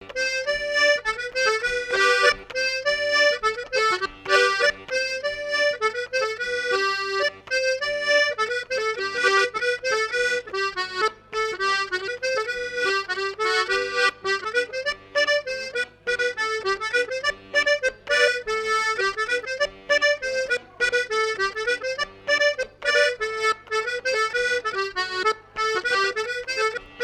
Chants brefs - A danser
danse : polka piquée
Fête de l'accordéon
Pièce musicale inédite